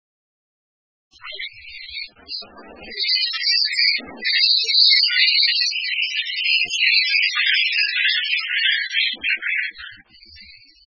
〔ムクドリ〕リャーリャー／ジャージャー／平地や村落などの疎林に棲息，普通・留鳥,
mukudori.mp3